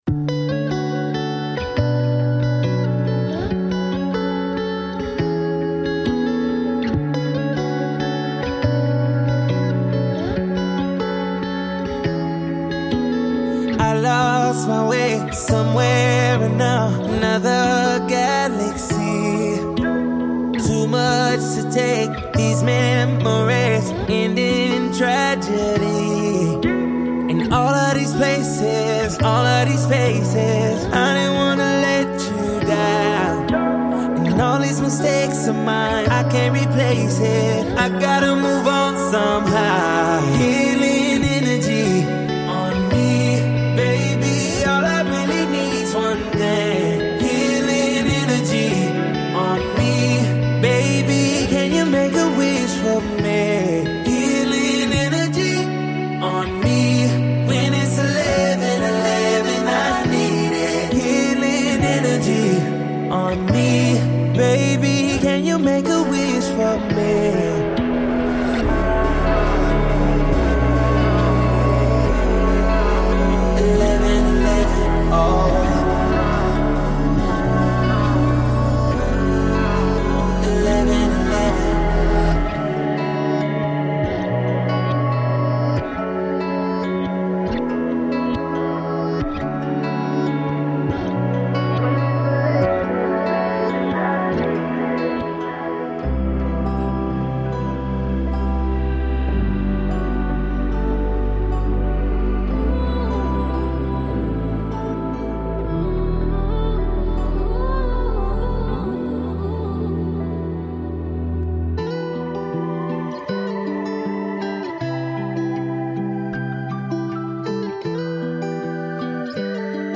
R&B, pop, and smooth beats